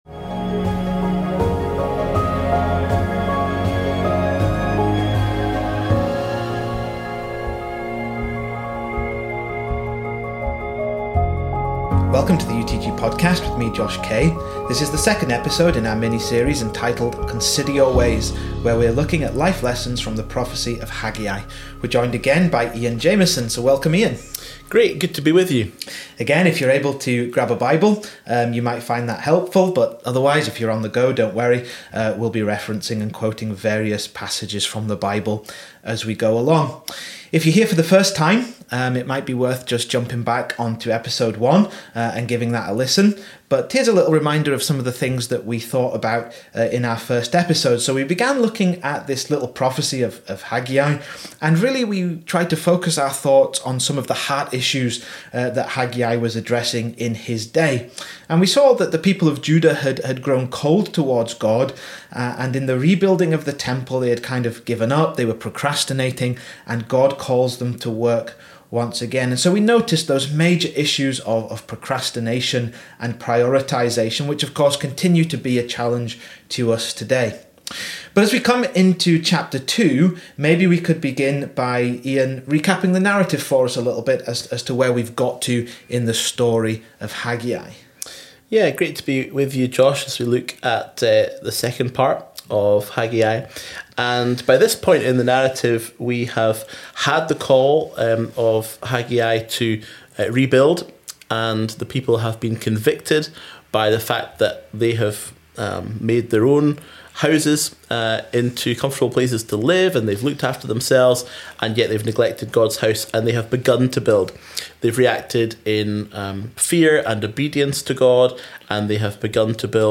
Join us for a challenging conversation, as we respond to God’s call to consider our ways.